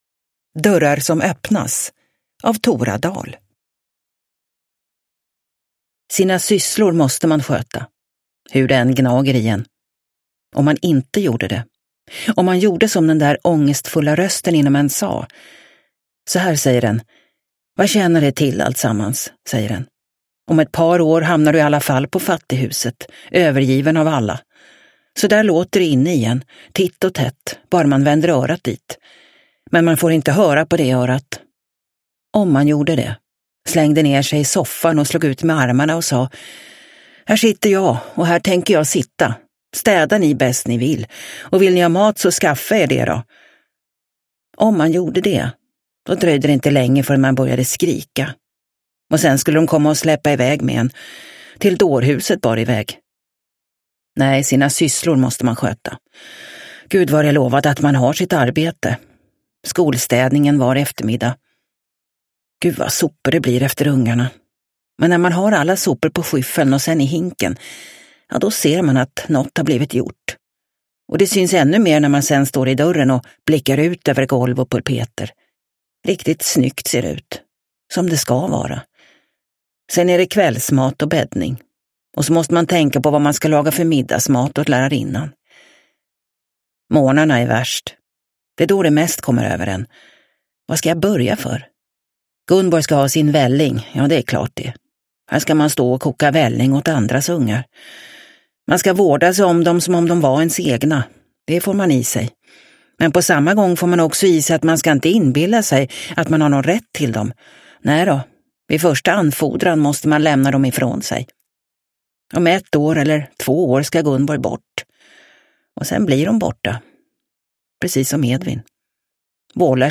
Dörrar som öppnas – Ljudbok – Laddas ner